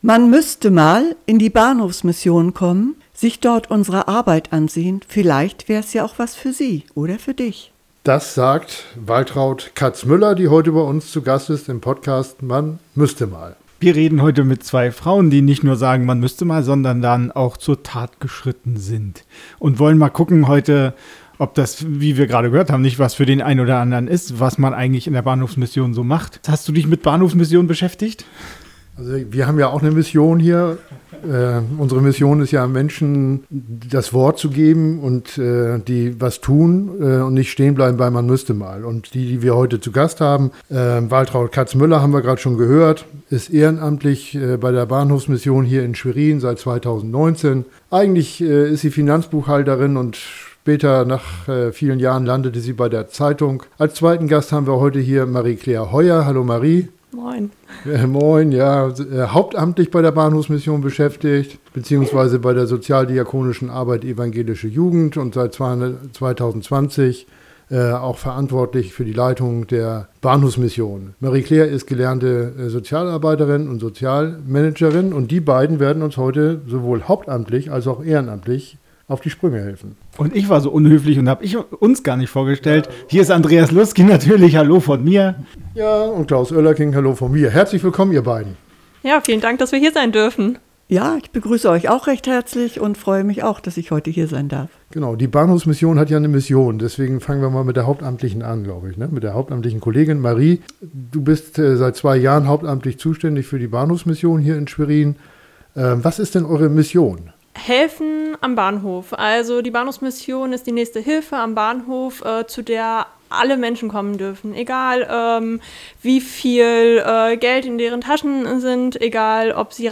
Und dieser Job macht ihr sichtlich und hörbar viel Freude.